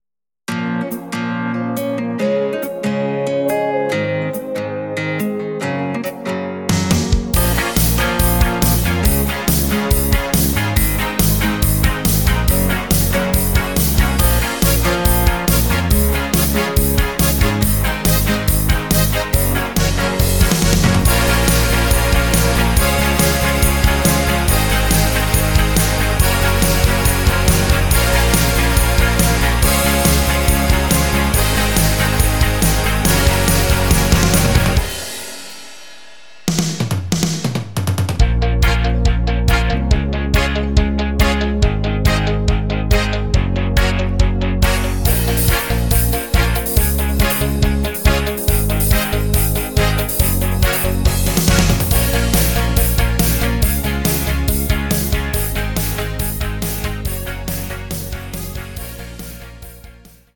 rockiger Austria Titel